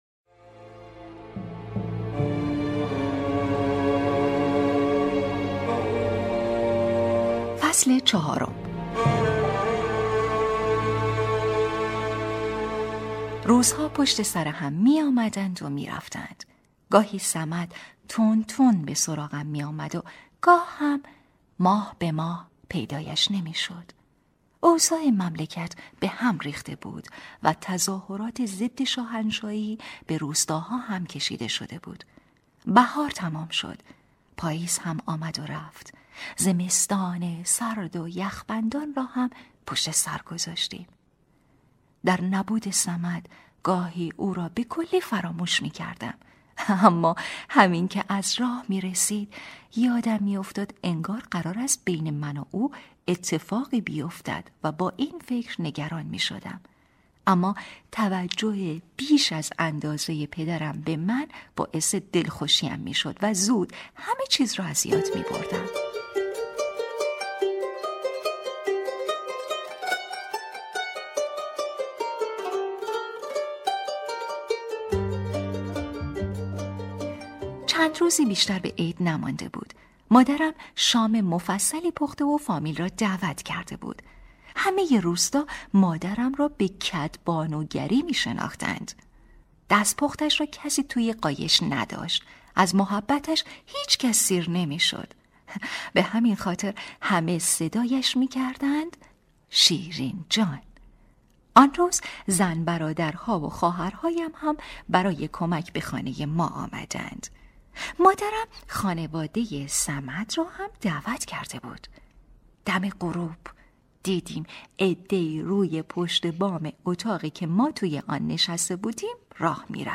کتاب صوتی | دختر شینا (04)